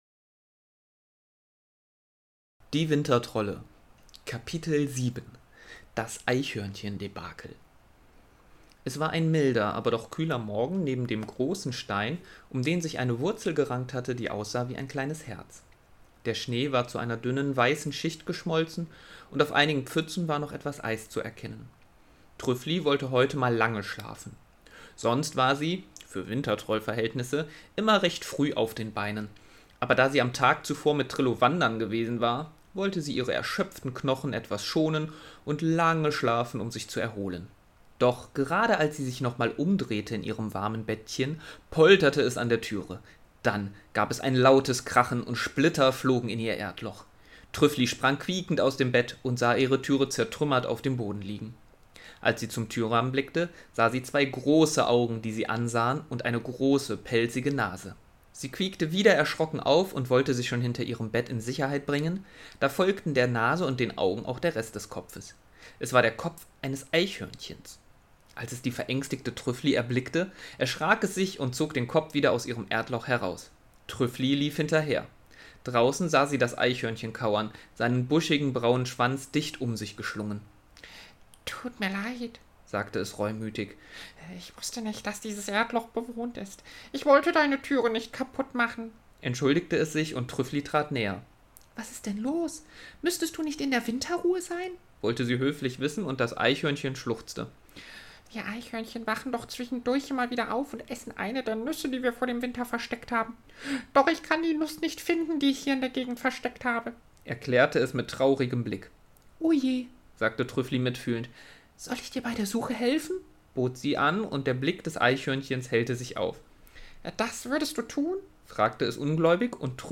In diesem Podcast werden kleine Geschichten für Kinder vorgelesen. Geeignet für Kinder ab 3 Jahren.